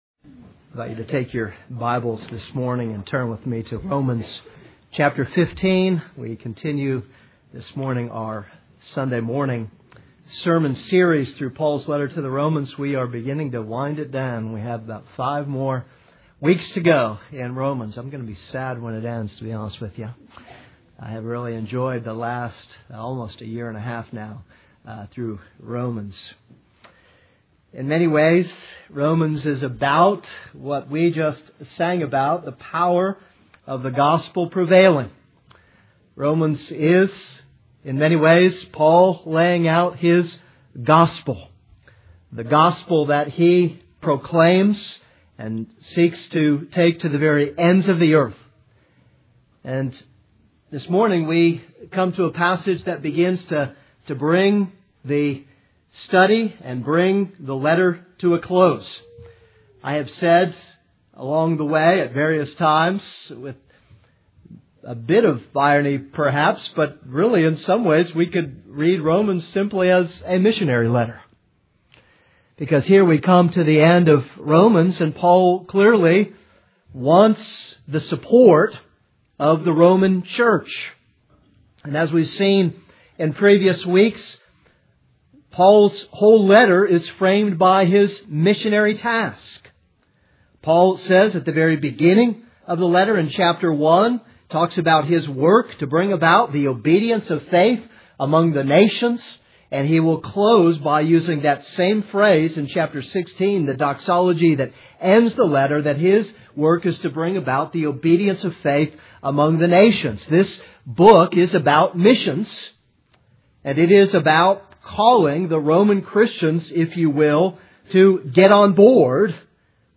This is a sermon on Romans 15:14-24.